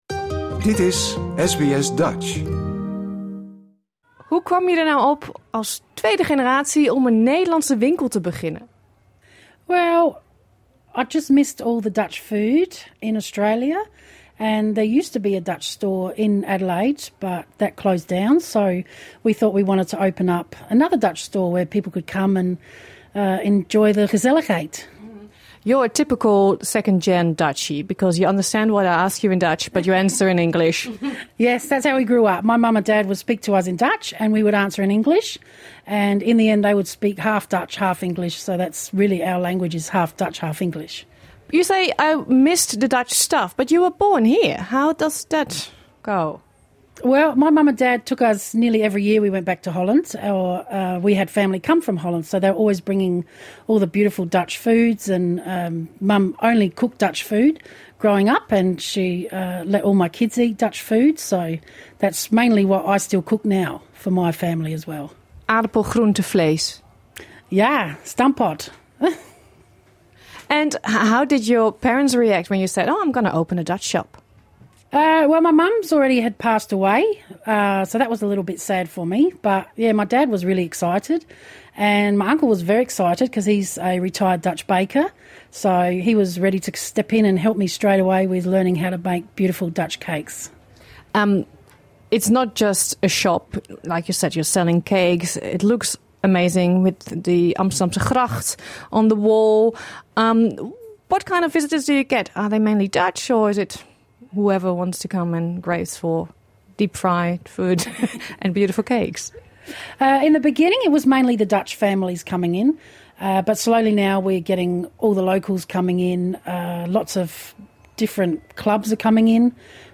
Dit interview is in het Engels.